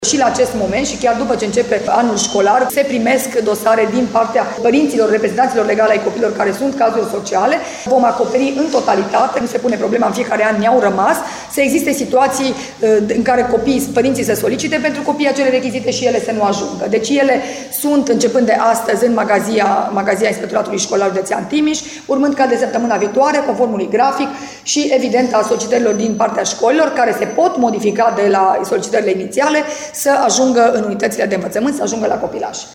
Toţi cei care se încadrează vor primi răspuns favorabil, dă asigurări şeful inspectoratului şcolar din judeţ, Aura Danielescu.